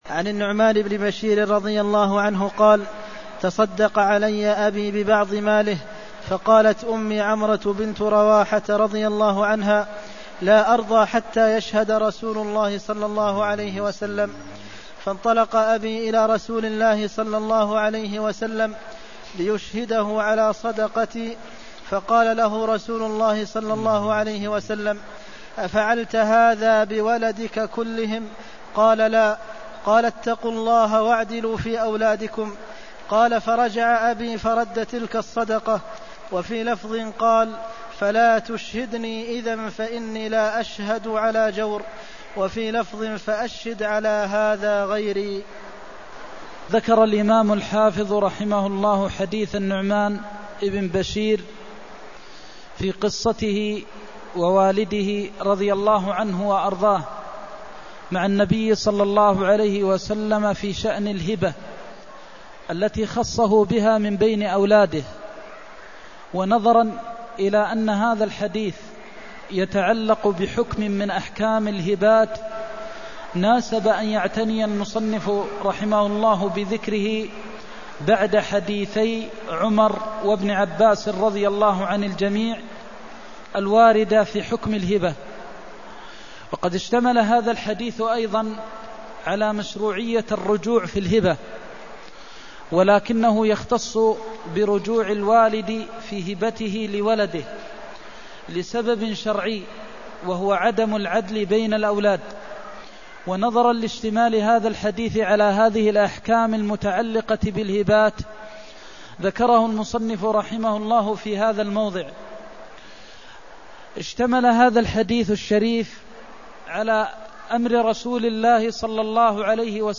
المكان: المسجد النبوي الشيخ: فضيلة الشيخ د. محمد بن محمد المختار فضيلة الشيخ د. محمد بن محمد المختار اتقوا الله واعدلوا في أولادكم (271)